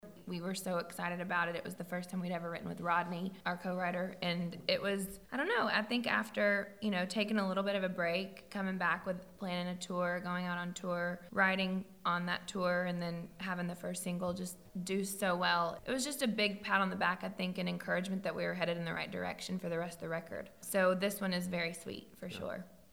AUDIO: Lady Antebellum’s Hillary Scott says the band felt very encouraged after “Bartender” went to No. 1.